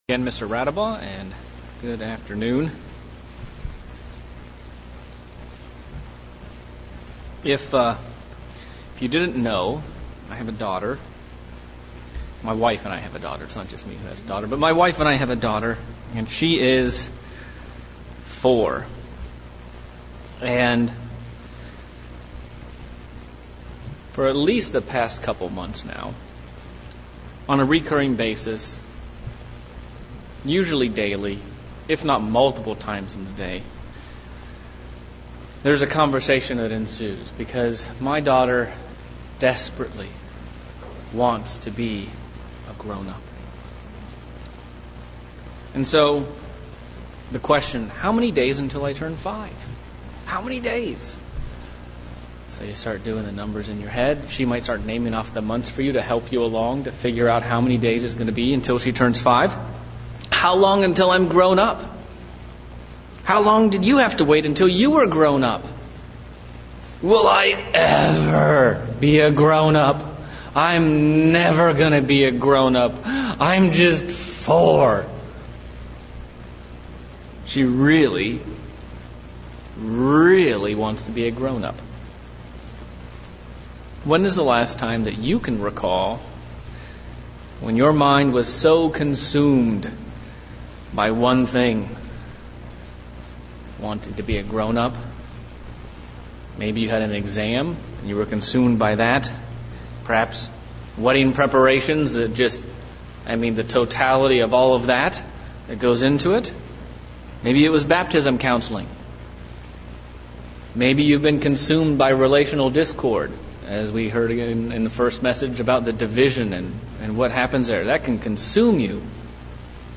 Sermon looking at the subject of hope. Looking at 6 areas of what we should earnestly hope for and desire.